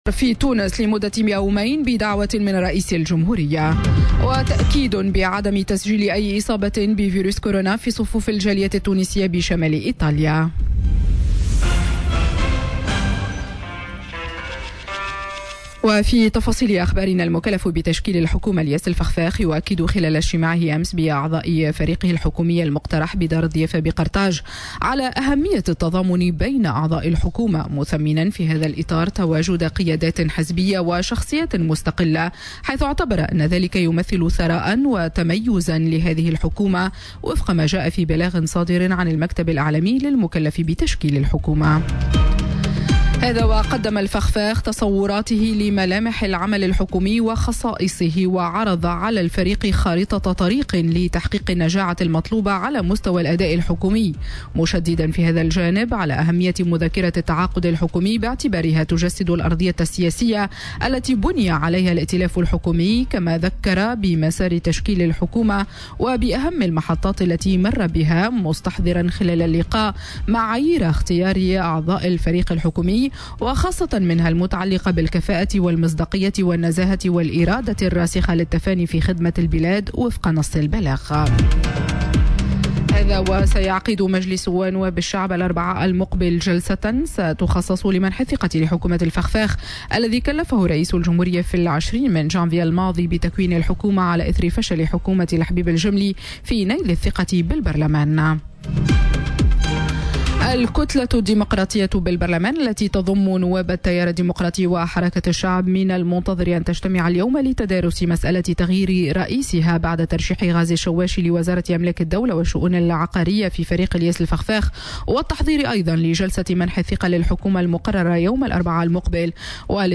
نشرة أخبار السابعة صباحا ليوم الإثنين 24 فيفري 2020